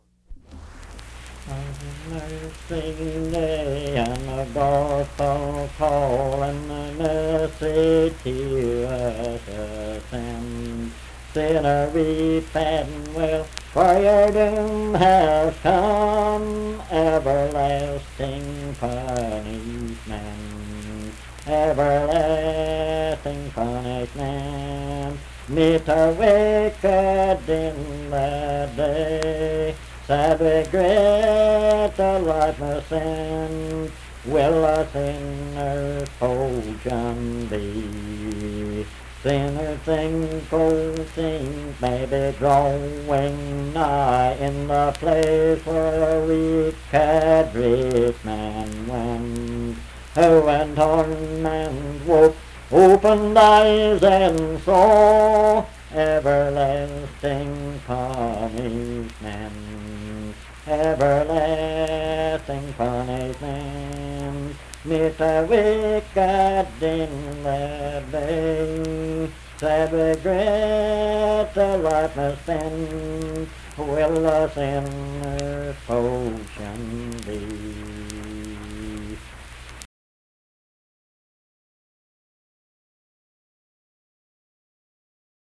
Unaccompanied vocal music
Verse-refrain 2(6). Performed in Dundon, Clay County, WV.
Hymns and Spiritual Music
Voice (sung)